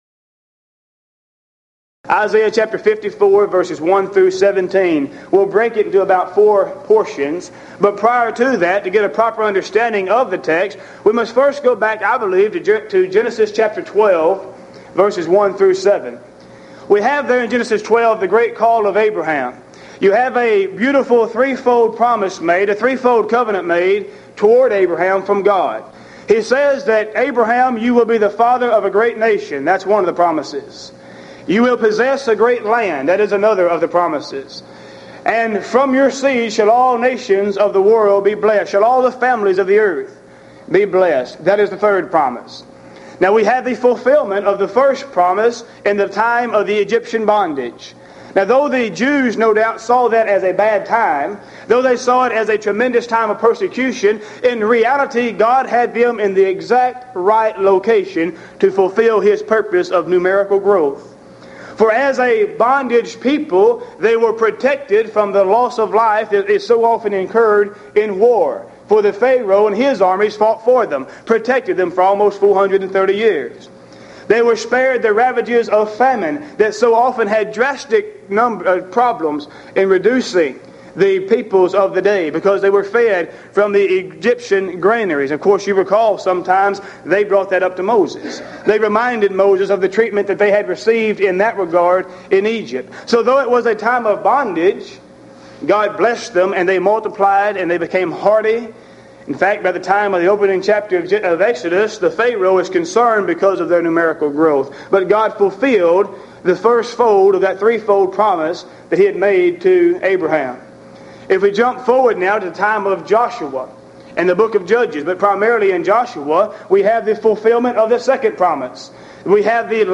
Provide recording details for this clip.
Event: 1996 HCB Lectures